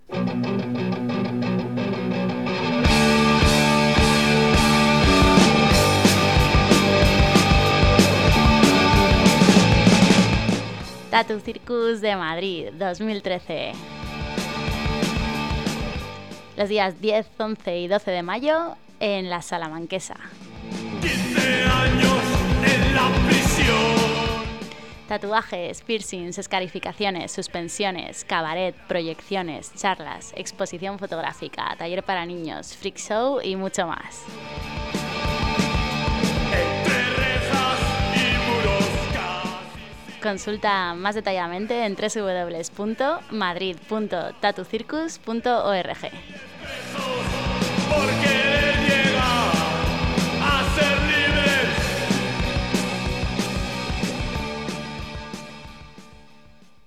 Asunto: Cuña Tattoo Circus Madrid 2013
Os envío la cuña que se hizo desde Radio ELA para el Tattoo Circus